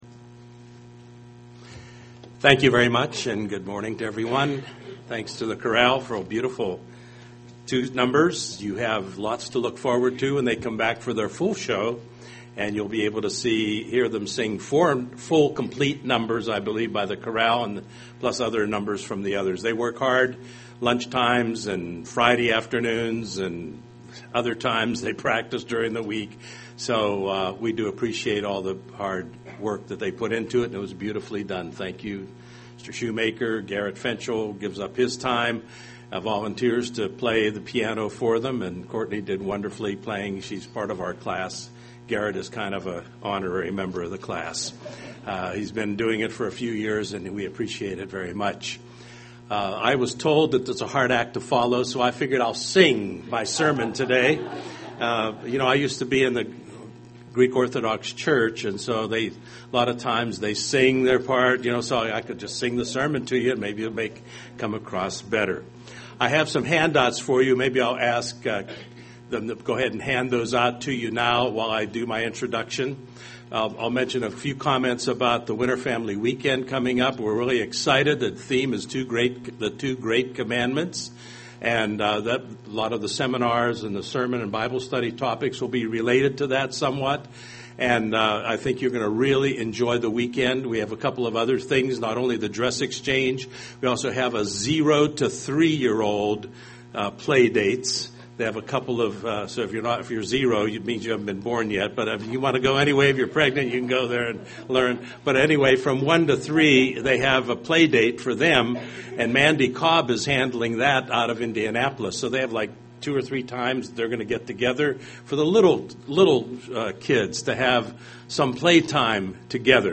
Some helpful points are discussed in this sermon.